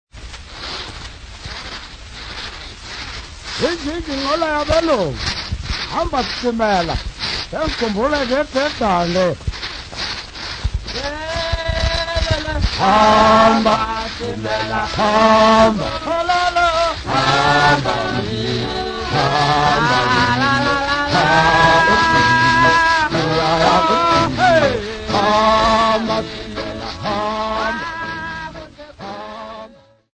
Natal Champions
Folk Music
Field recordings
sound recording-musical
Indigenous music